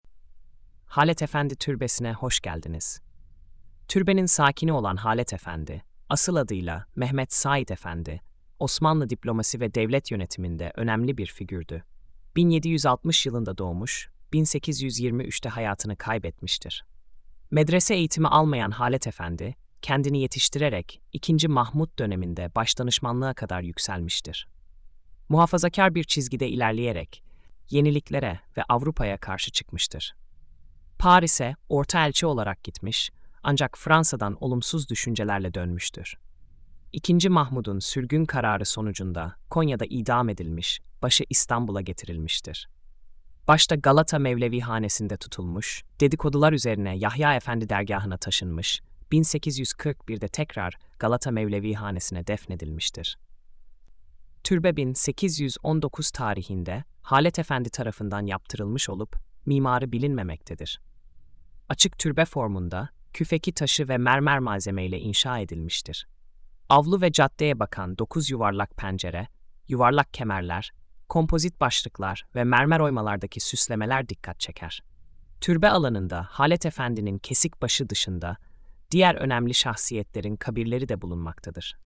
SESLİ ANLATIM: